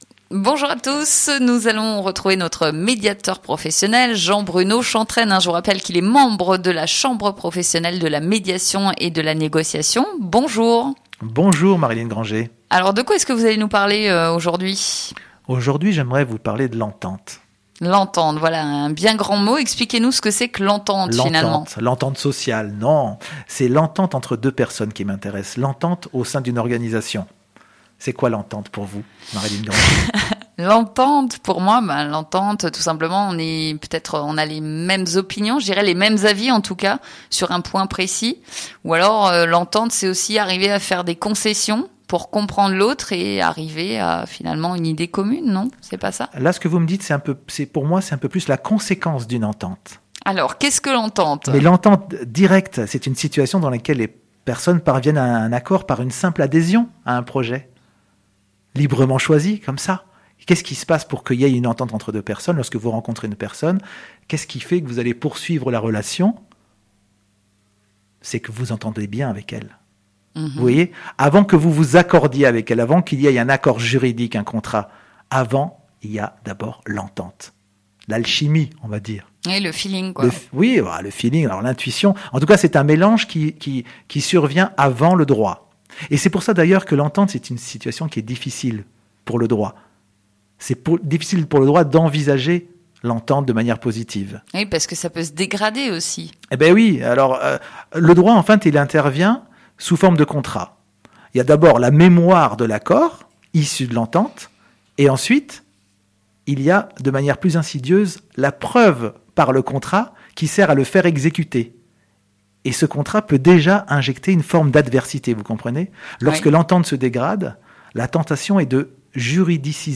Il nous propose ici une série d’enregistrements de sa chronique sur Sud FM